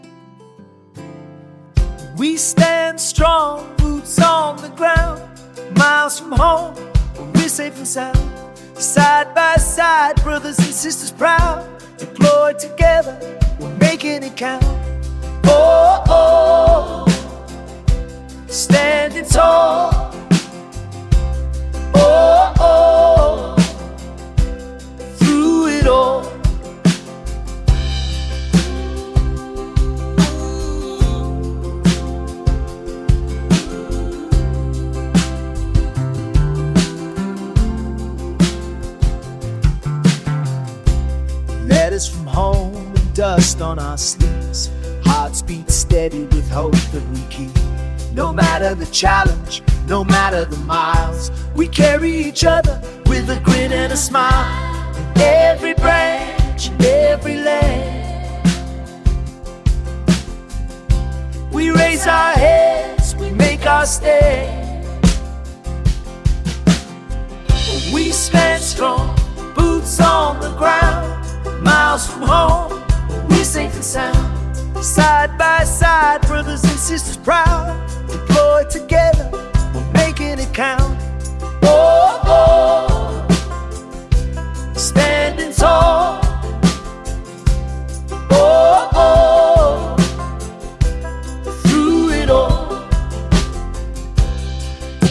AI music created in the MusicKraze iPhone app
Style: Rock
Tags: Country